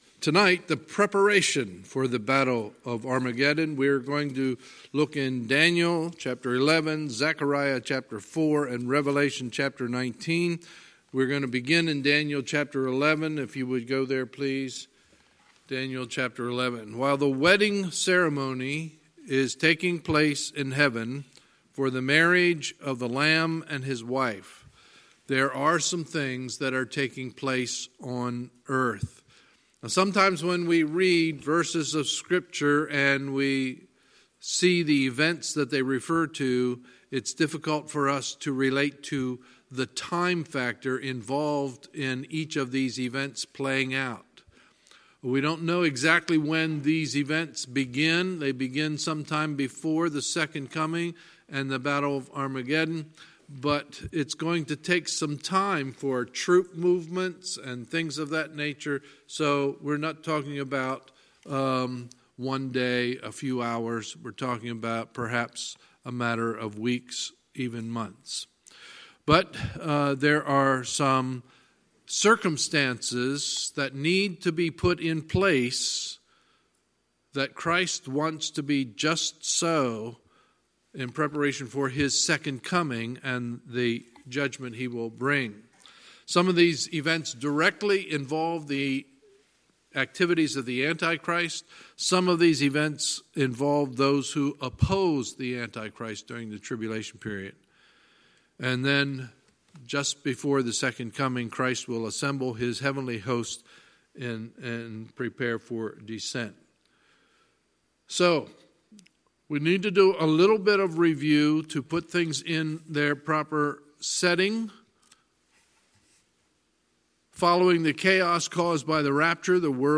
Sunday, July 14, 2019 – Sunday Evening Service
Sermons